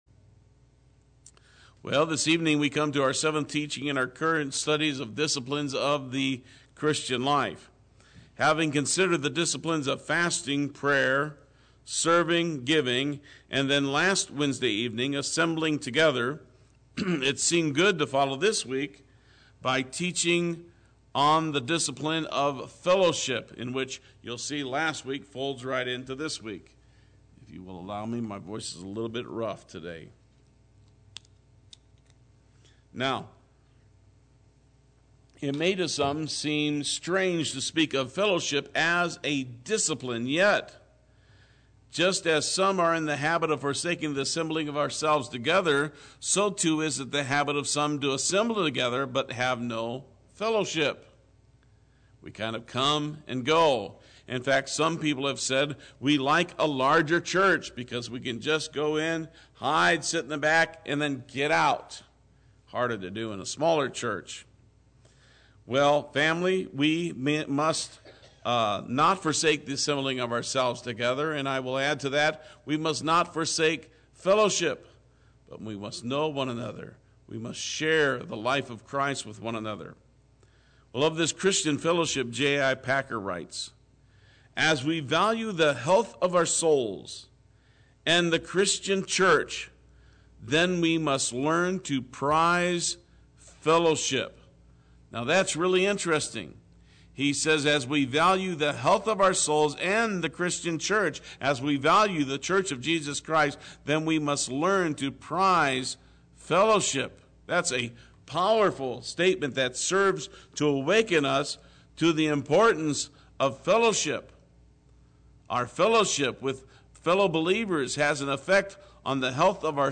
Play Sermon Get HCF Teaching Automatically.
Fellowship Wednesday Worship